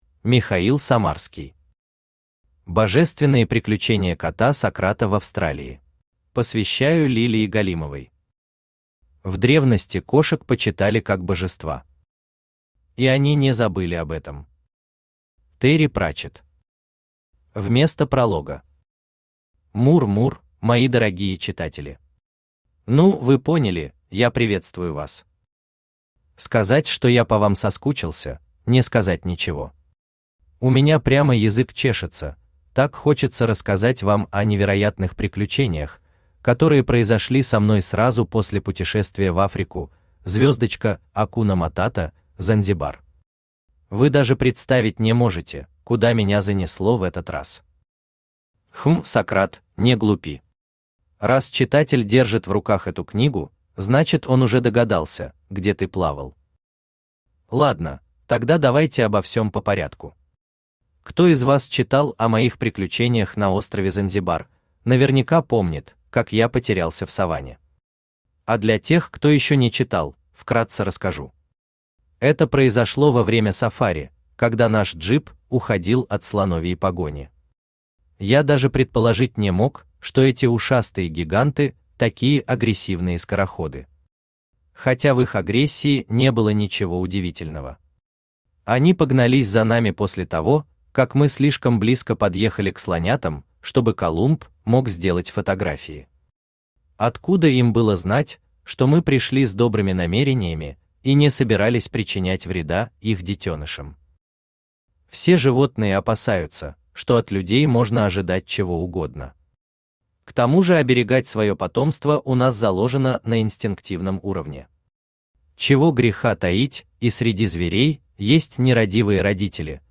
Аудиокнига Божественные приключения кота Сократа в Австралии | Библиотека аудиокниг
Aудиокнига Божественные приключения кота Сократа в Австралии Автор Михаил Самарский Читает аудиокнигу Авточтец ЛитРес.